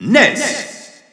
Announcer pronouncing Ness's name in French.
Ness_French_Announcer_SSBU.wav